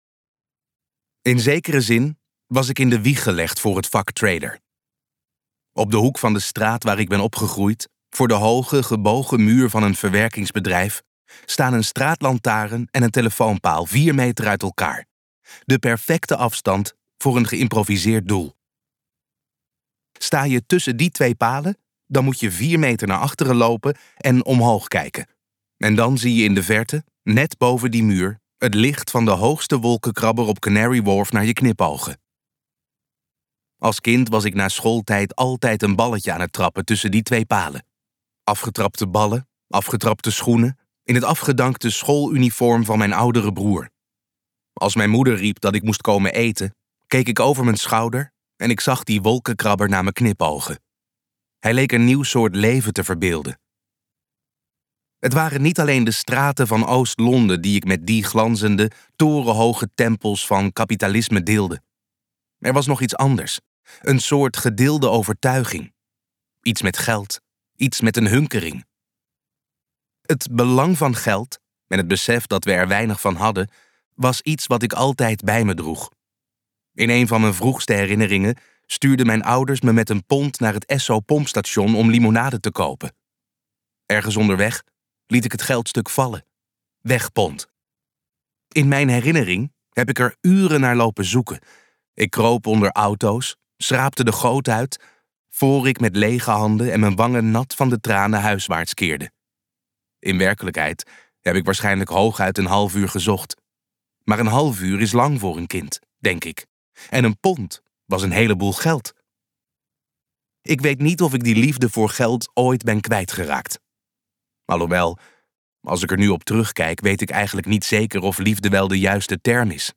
Ambo|Anthos uitgevers - Spelen met geld luisterboek